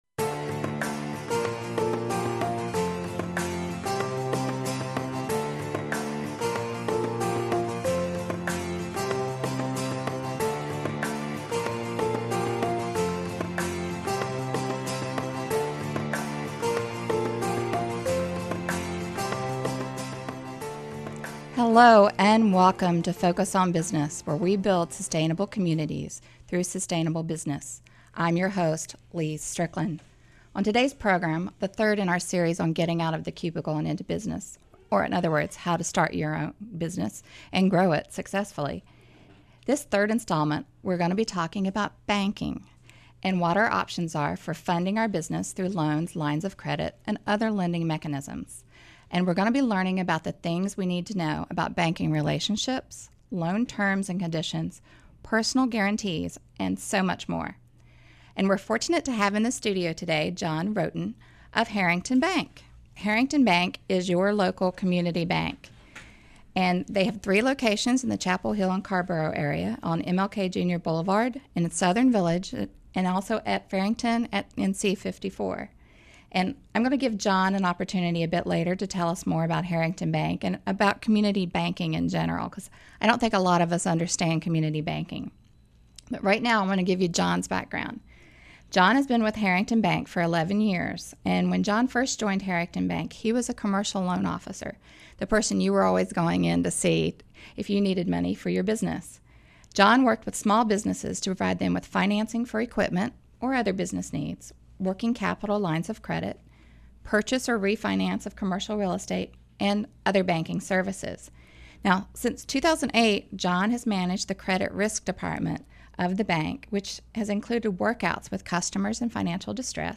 Focus on Business Radio Show